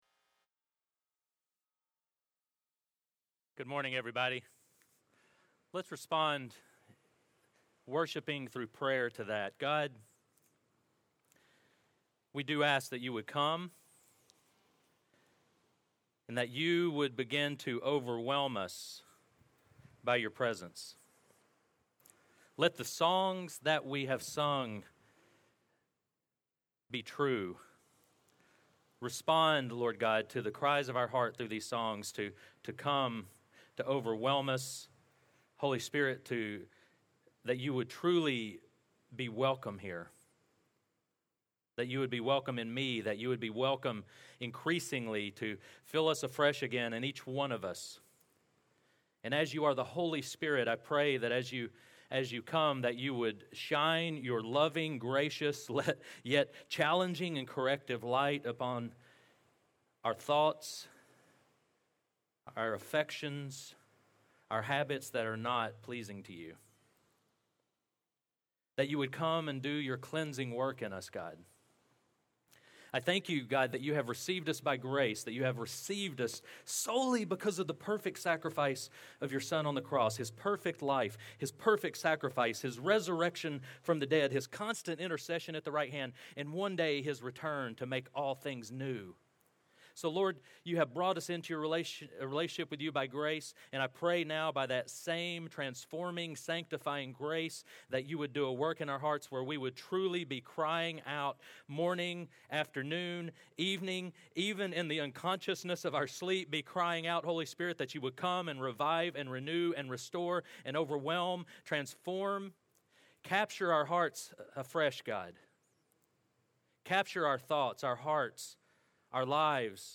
Sermons | Restoration Community Church